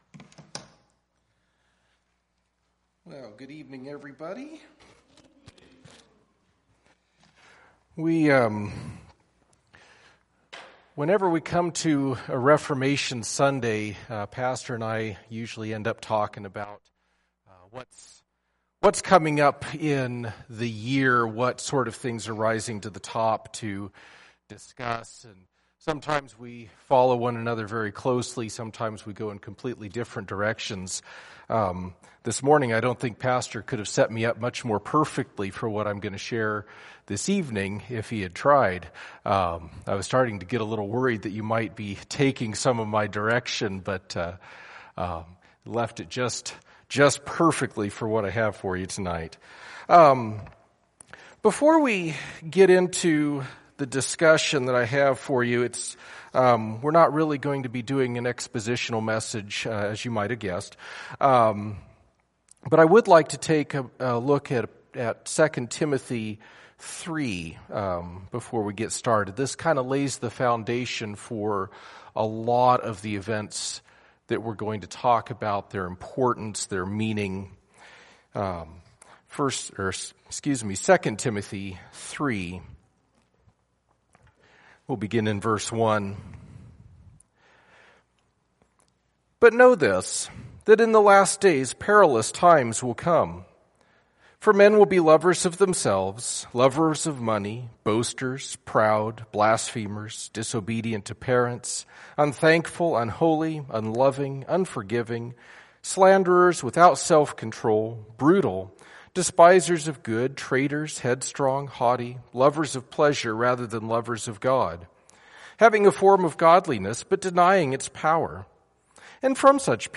Reformation Passage: 2 Timothy 3:1-17 Service Type: Sunday Evening Topics